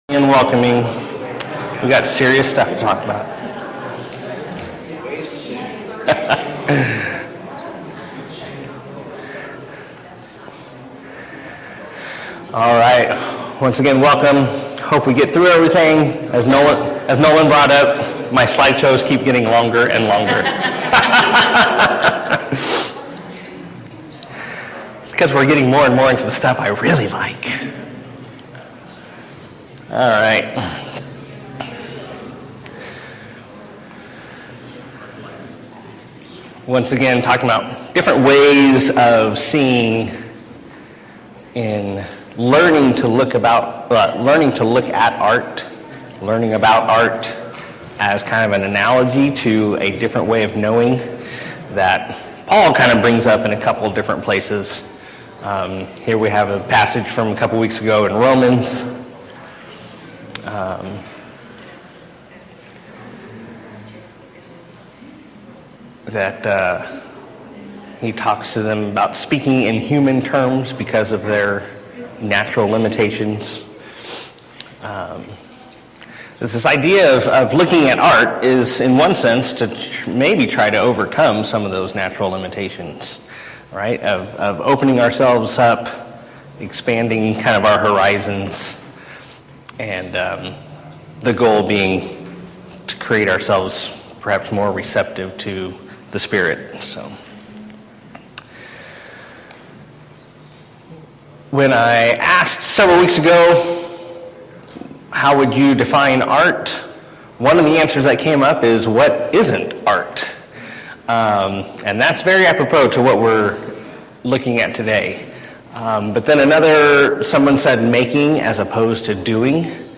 Passage: 1 Corinthians 2 Service Type: 9:30 Hour - Class